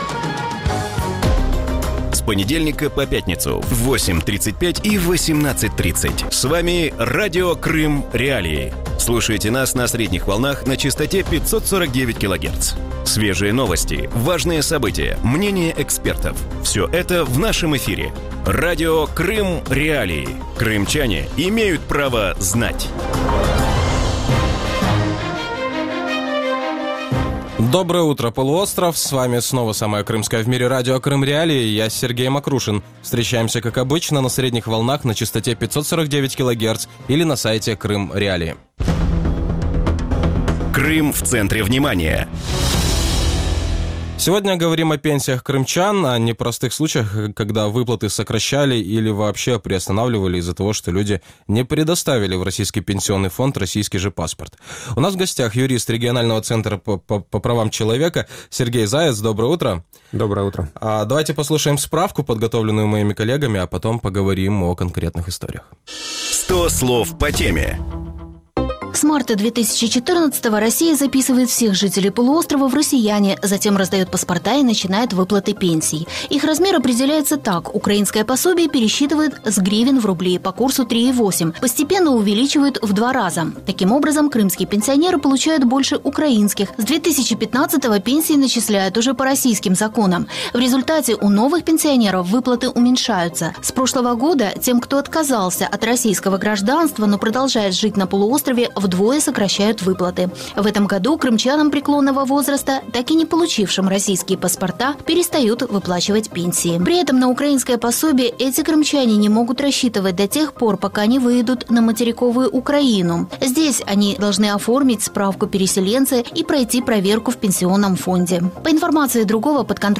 Утром в эфире Радио Крым.Реалии говорят о том, что делать крымчанам, которым Пенсионный фонд России уменьшил или вообще приостановил выплату пенсий.